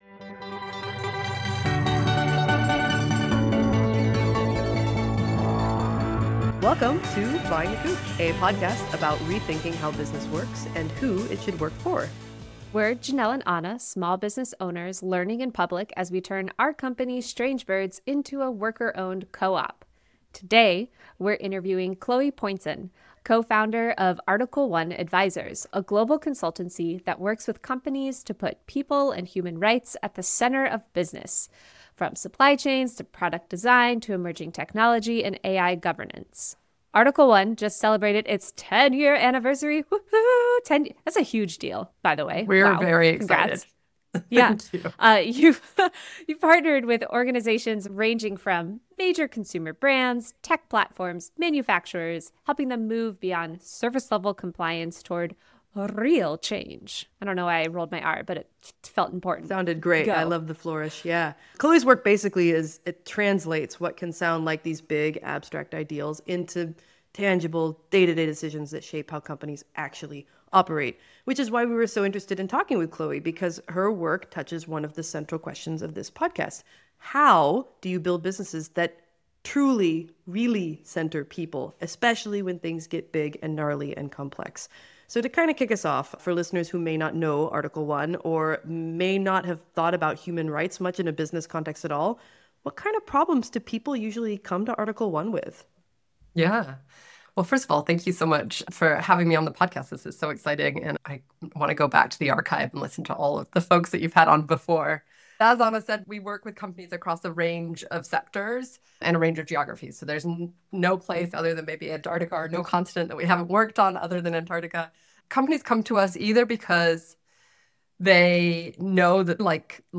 This interview covers a lot of ground with a lot of heart.